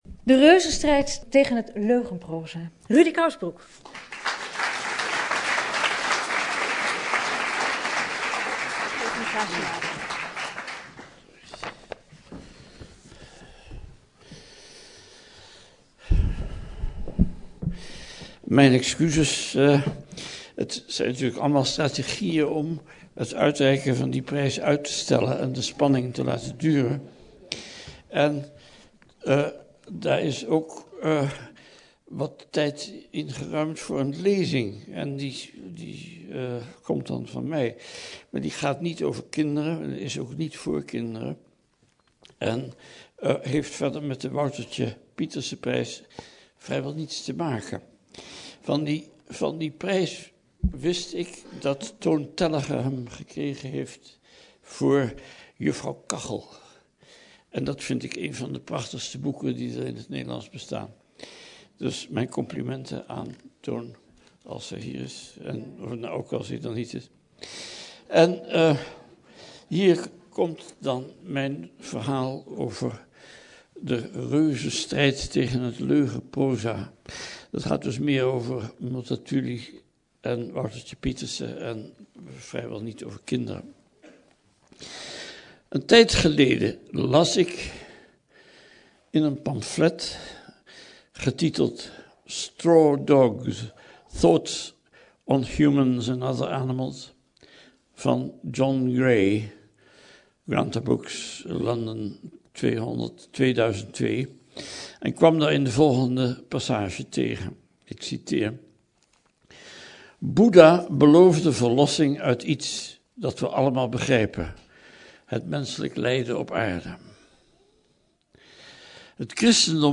Rudy Kousbroek, Woutertje Pieterse lezing 2007